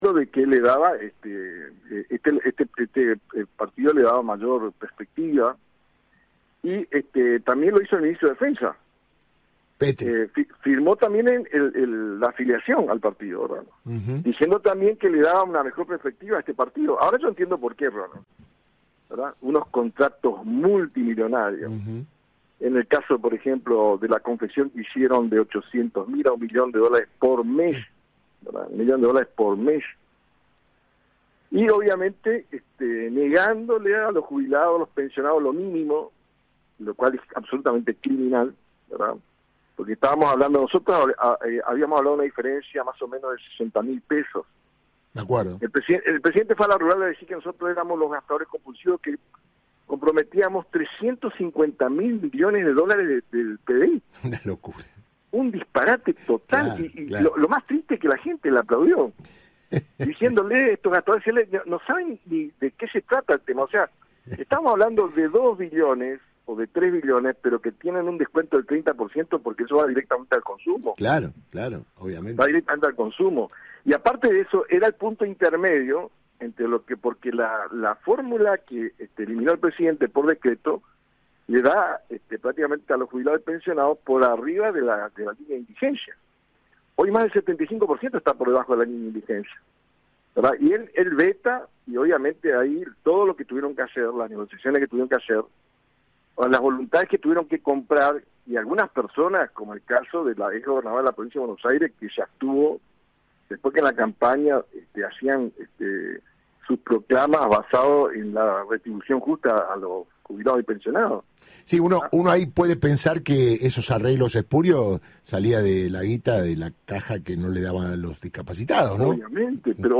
Hay que cuidarlo porque peligra su vida porque sabe mucho”, alertó Mayans en una entrevista con Radio 10.